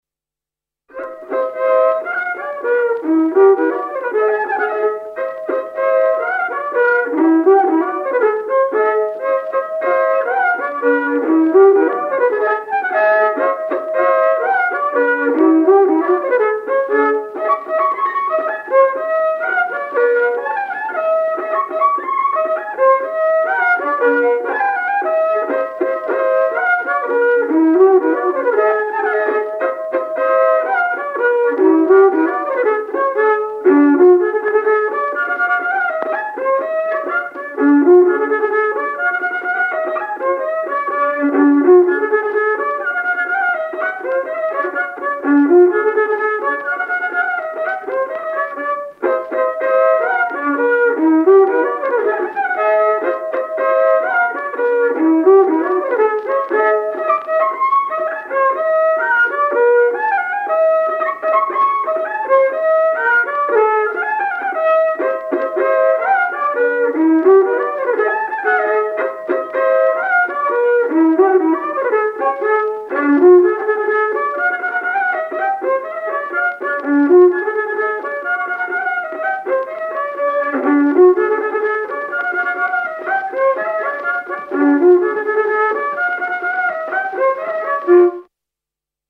23 Sipelga polka.mp3